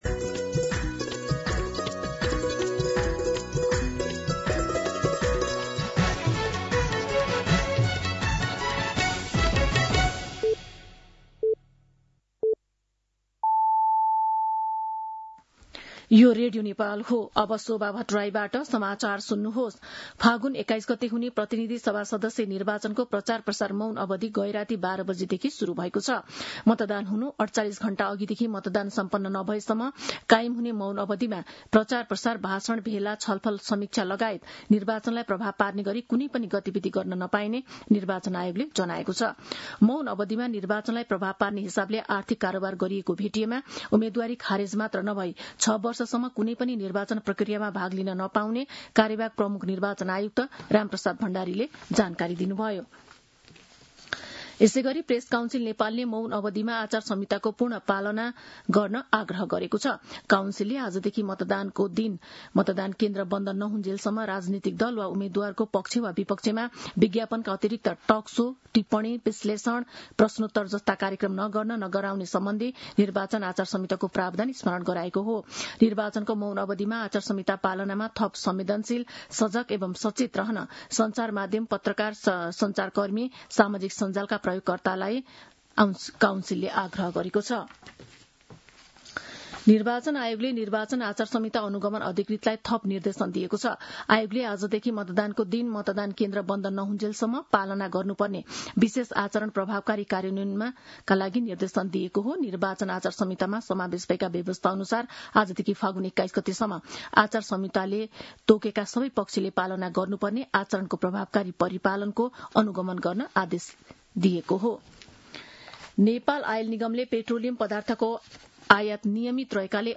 दिउँसो १ बजेको नेपाली समाचार : १९ फागुन , २०८२